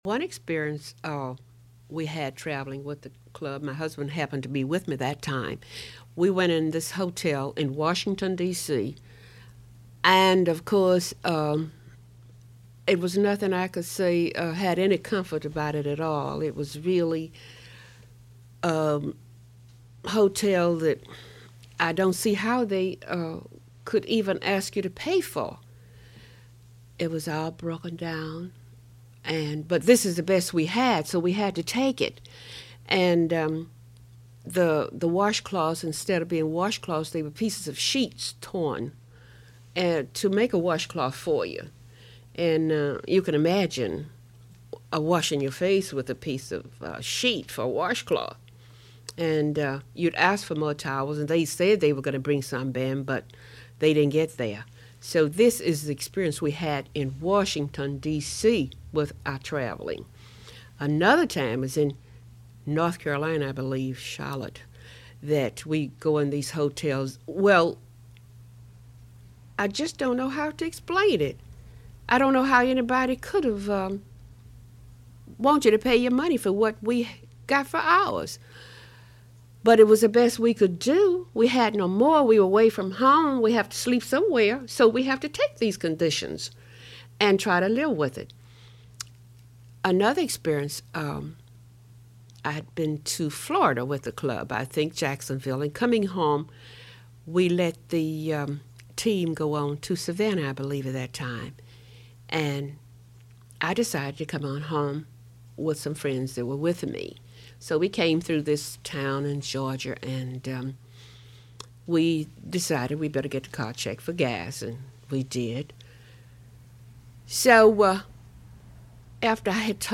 Radio Free Georgia has even partnered with Atlanta History Center to record a fascinating series of oral histories that detail the experiences of Atlantans great and small between the first and second World Wars.